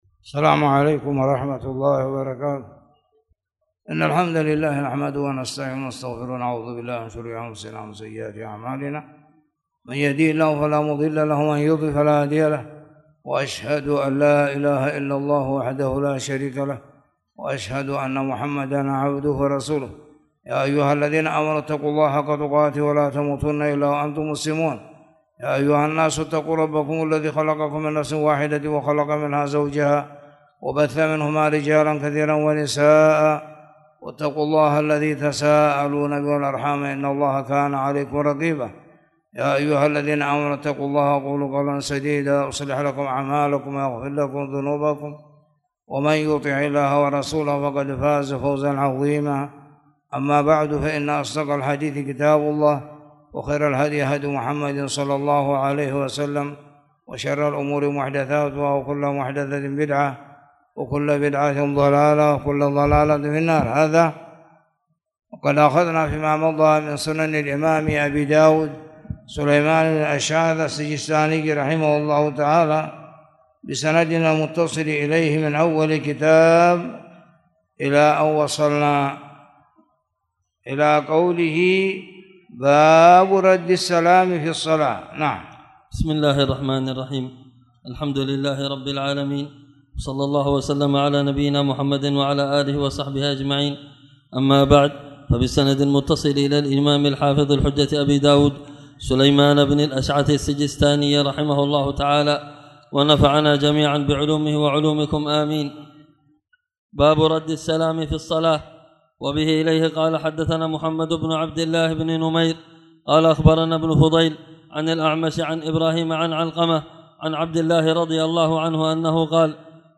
تاريخ النشر ٧ ربيع الأول ١٤٣٨ هـ المكان: المسجد الحرام الشيخ